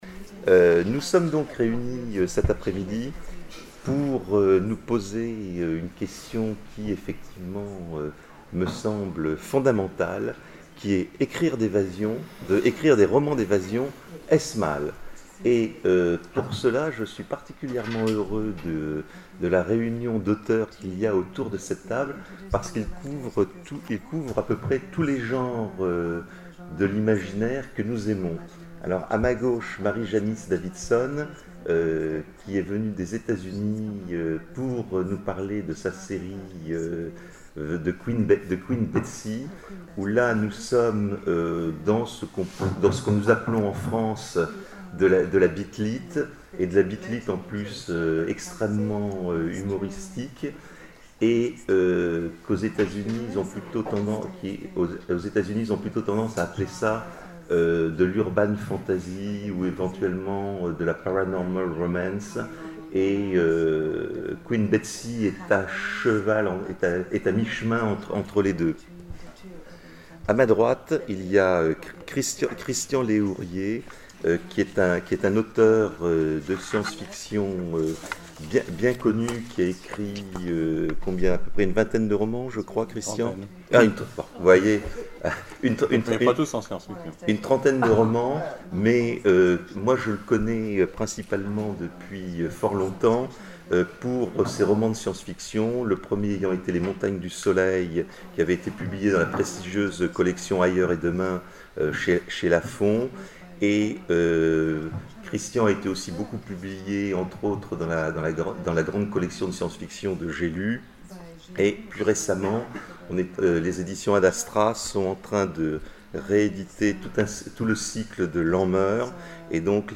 Imaginales 2012 : Conférence écrire des romans d'évasion, est-ce mal ?